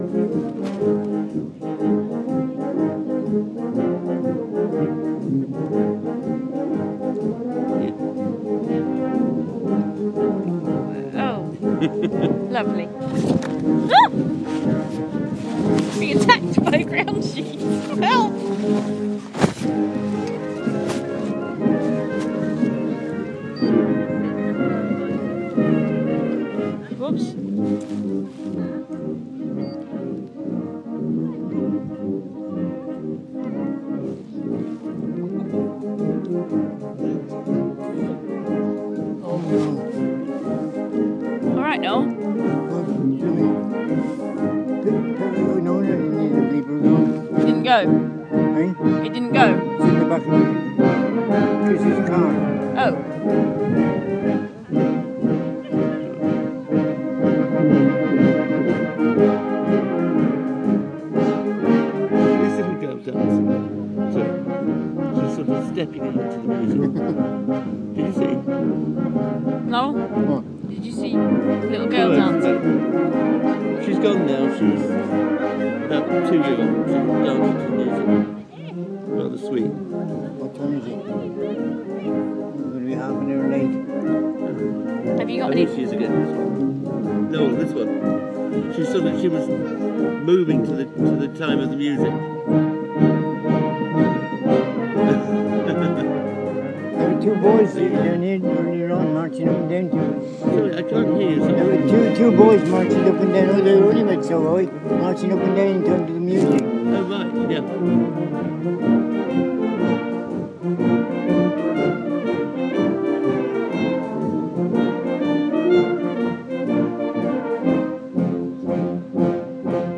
Lincolnshire Poacher on brass